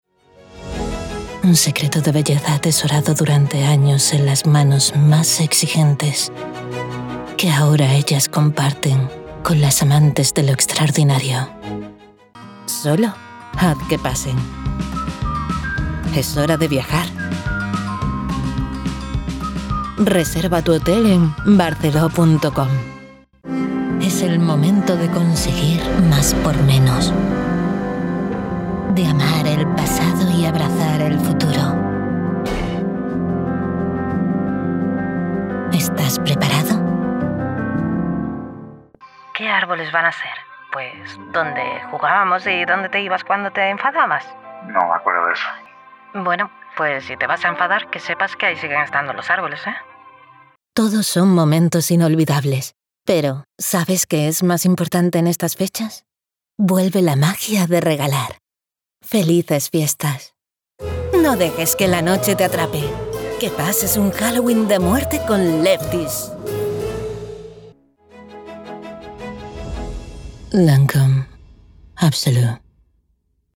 Soy una locutora espaĂ±ola con acento espaĂ±ol castellano neutro. Voz femenina comercial, publicitaria, natural, convincente, elegante, juvenil, adulta.
Sprechprobe: Werbung (Muttersprache):
I have my own professional soundproof studio.